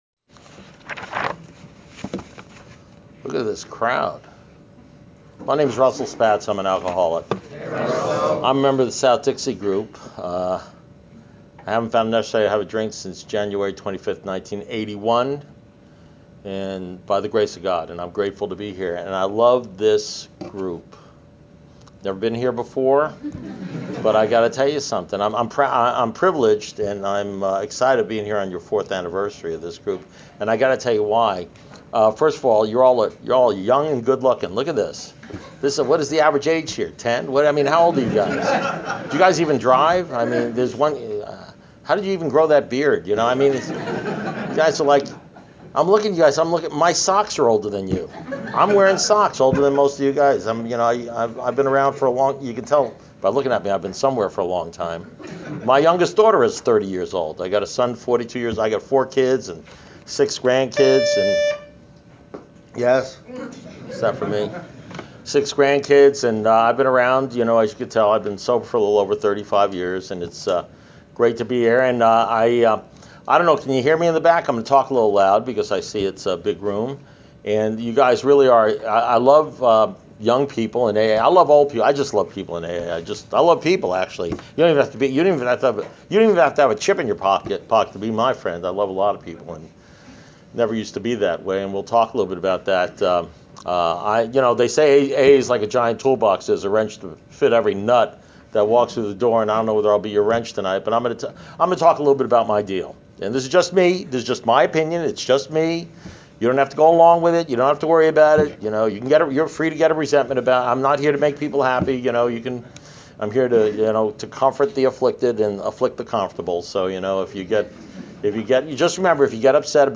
Alcoholics Anonymous Speaker Recordings
Tuesday, March 8th, 2016 – Anniversary Meeting at the Pennsylvania Ave. Speaker Group, Towson, MD.